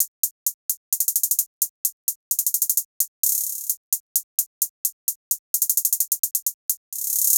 HiHat (38).wav